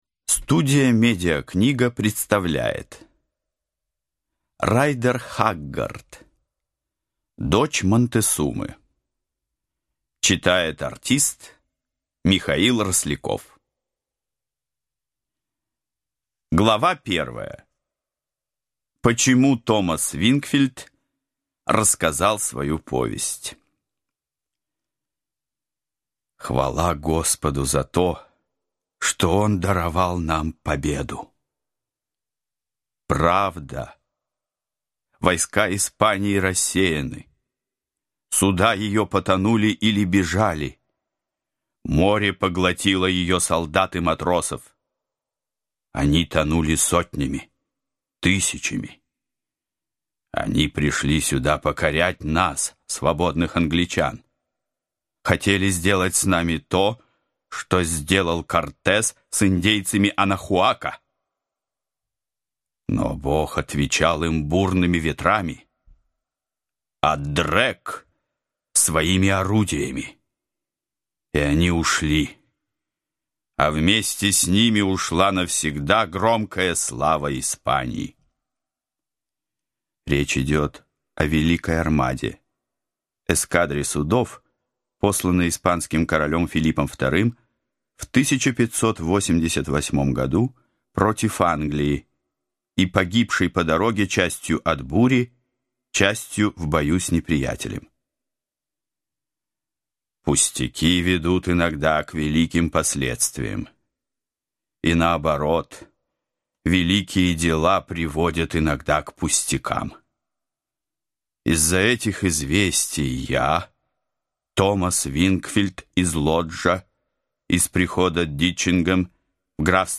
Аудиокнига Дочь Монтесумы | Библиотека аудиокниг